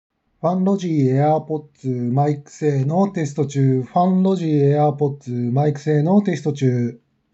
サ行が刺さりやすい
こもったりしないがサ行刺さりやすマイク性能
✅FUNLOGY Earbudsマイク性能
音がこもったりは少ないが少し中高音域が刺さりやすくなっています。
この値段帯だとかなり珍しいです。たいていはこもったような音になることが多いですから。